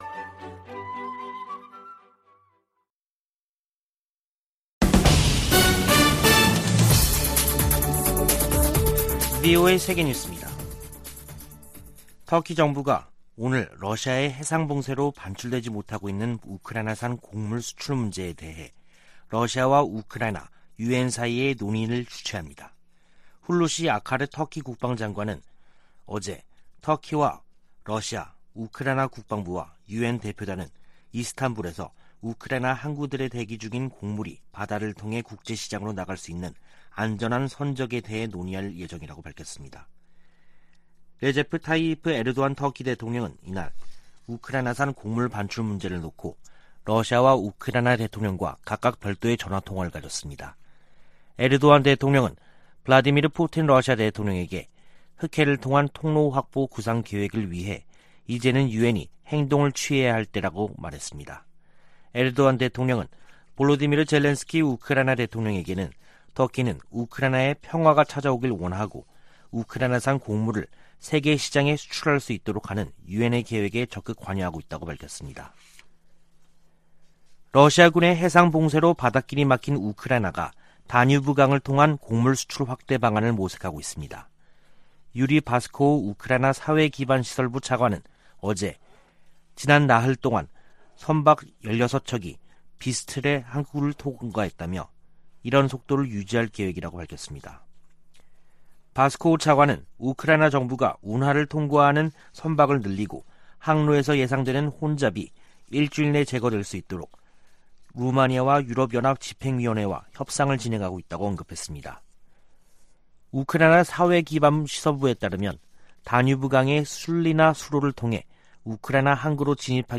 VOA 한국어 간판 뉴스 프로그램 '뉴스 투데이', 2022년 7월 13일 3부 방송입니다. 전임 도널드 트럼프 미국 행정정부에서 고위 관료를 지낸 인사들이 강력한 대북 제재로, 김정은 국무위원장이 비핵화의 길로 나오도록 압박해야 한다고 말했습니다. 미 국무부 선임고문이 한국 고위 당국자들을 만나 양국 관계 강화와 국제 현안 협력 방안을 논의했습니다. 북한이 최근 방사포를 발사한 것과 관련해 주한미군은 강력한 미한 연합방위태세를 유지하고 있다고 밝혔습니다.